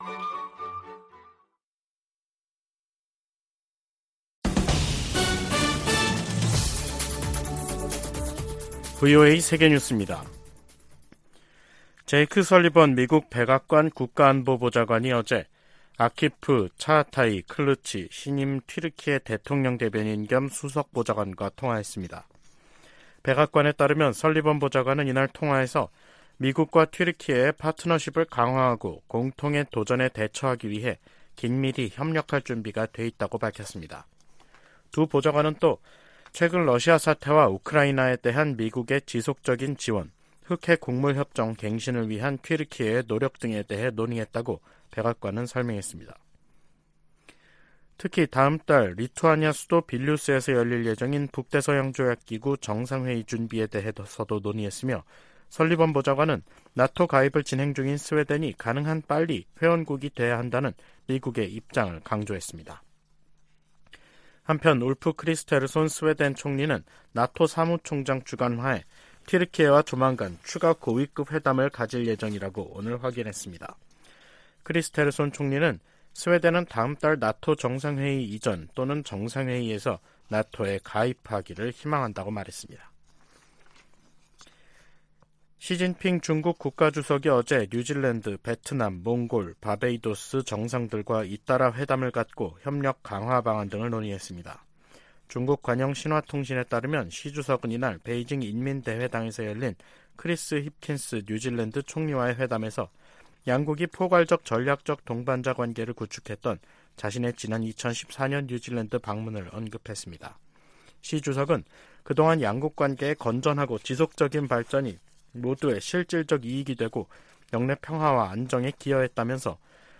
VOA 한국어 간판 뉴스 프로그램 '뉴스 투데이', 2023년 6월 28일 2부 방송입니다. 미 국무부는 미국과 한국의 군사활동 증가와 공동 핵계획 탓에 한반도 긴장이 고조되고 있다는 중국과 러시아의 주장을 일축했습니다. 미 국방부는 북한의 핵무력 강화 정책 주장과 관련해 동맹국과 역내 파트너들과의 협력을 강조했습니다. 미 하원 세출위원회가 공개한 2024회계연도 정부 예산안은 북한과 관련해 대북 방송과 인권 증진 활동에만 예산을 배정하고 있습니다.